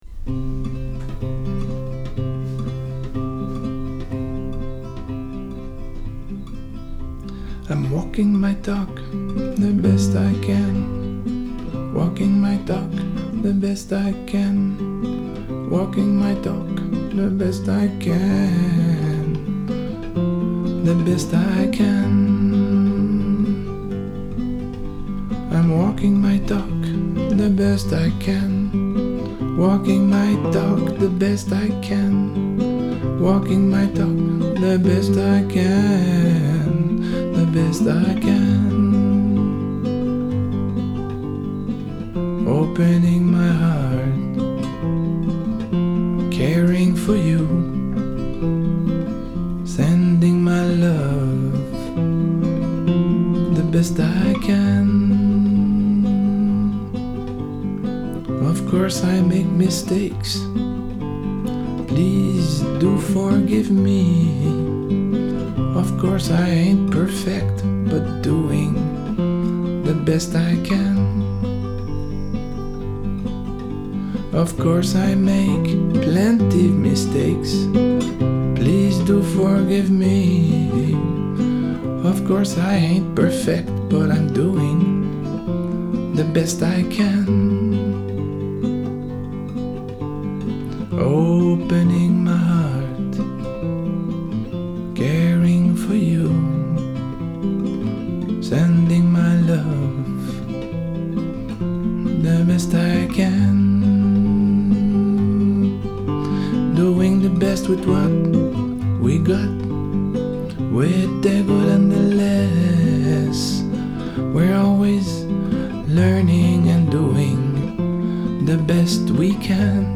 un hymne simple qui dit l’humilité, qui affirme en douceur la vulnérabilité du coeur, la soif de l’âme… et la nécessité de continuer à marcher son chemin du mieux que l’on peut… peu importe… parfois en groupe, et parfois seul(e)… parfois en harmonie, et parfois pas… parfois en dansant, parfois en trébuchant…